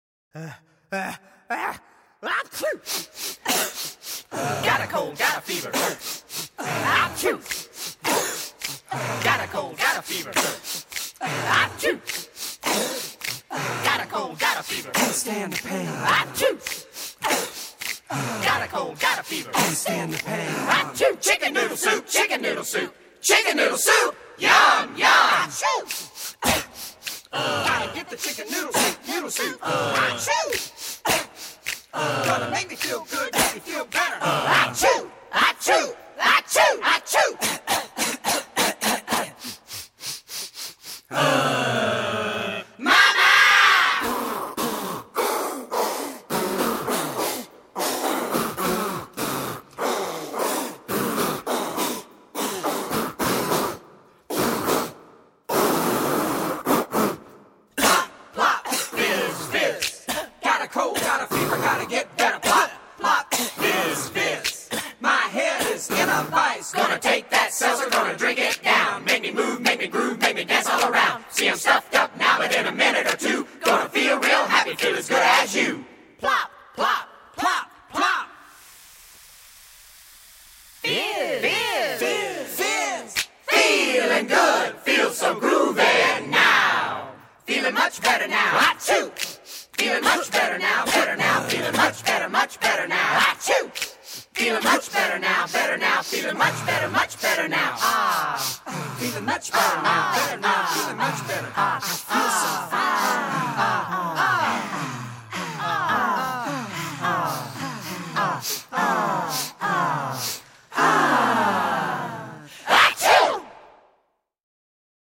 Voicing: Mallet Choir